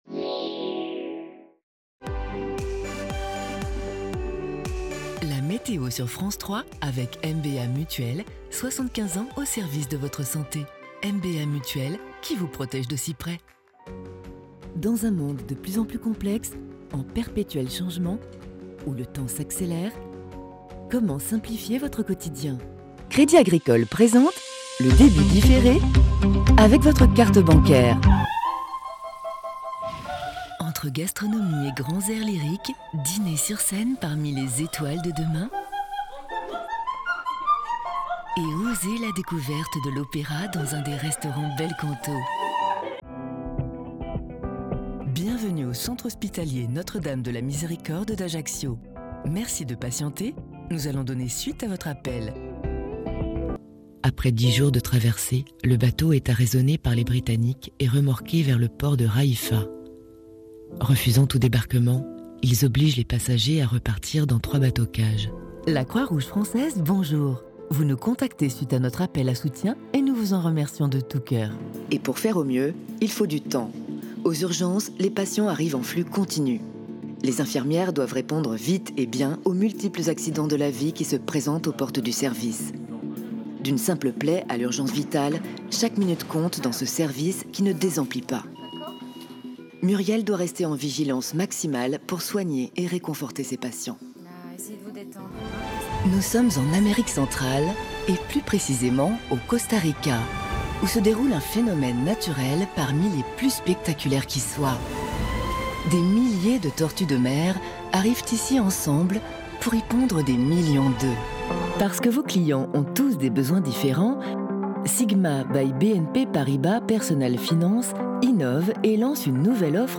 Voix Off Femme à Paris - Home Studio
Medley général